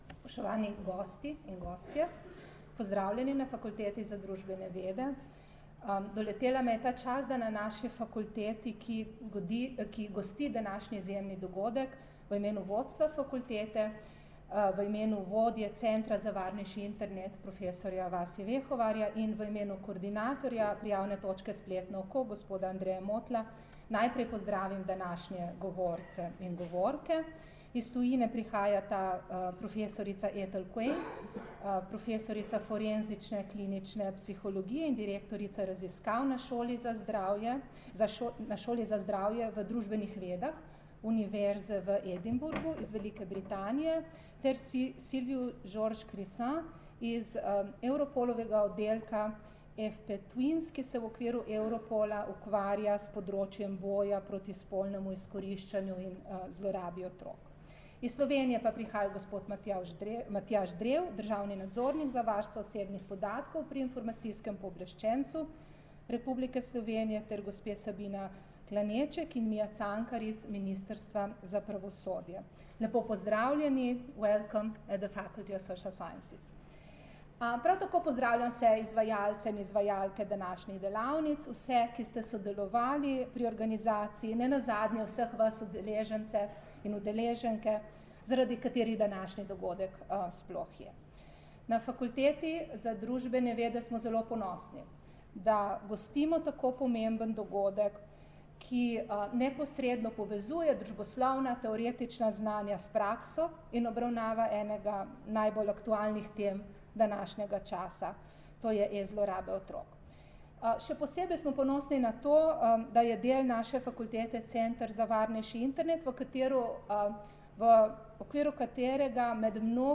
Na Fakulteti za družbene vede je včeraj, 27. septembra 2018, potekal osmi posvet na temo zlorab otrok na internetu z naslovom Obravnava e-zlorab otrok: iz teorije v prakso.
Zvočni posnetek nagovora